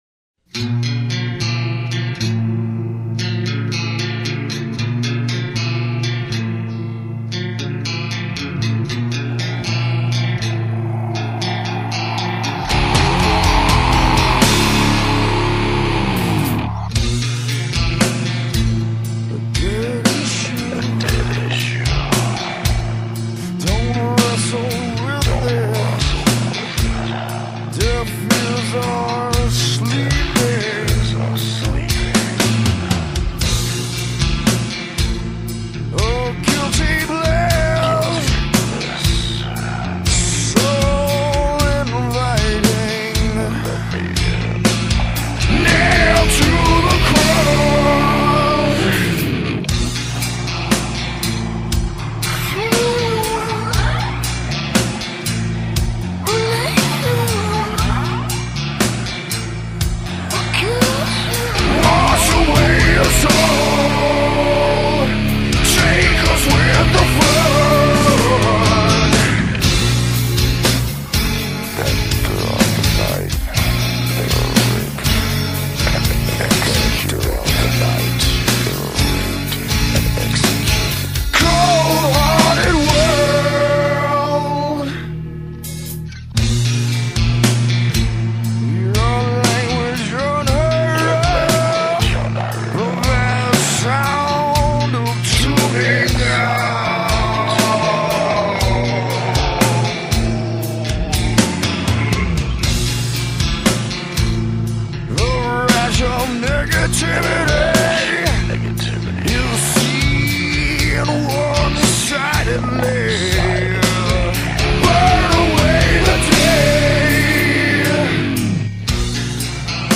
1996 Genres: Groove metal, Sludge Metal